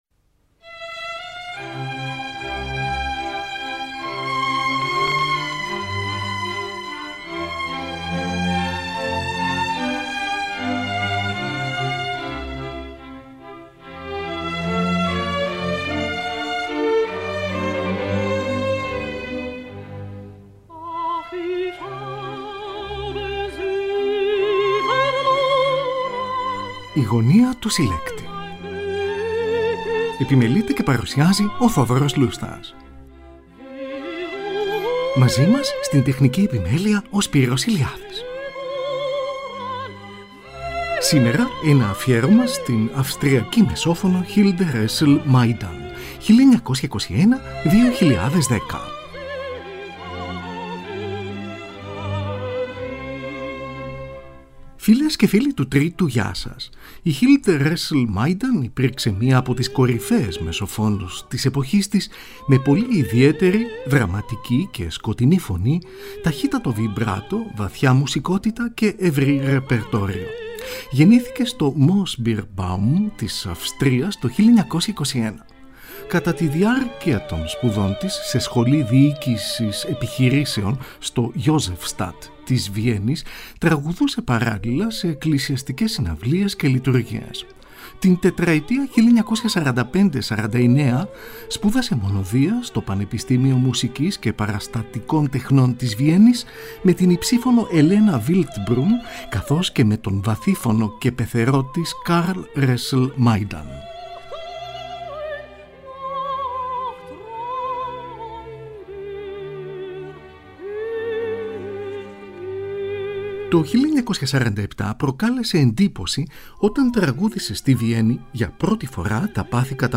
ΑΦΙΕΡΩΜΑ ΣΤΗΝ ΑΥΣΤΡΙΑΚΗ ΜΕΣΟΦΩΝΟ HILDE RÖSSEL-MAJDAN (1921-2010)
Ερμηνεύει συνθέσεις των Johann Sebastian Bach, Gustav Mahler και Christoph Willibald Gluck.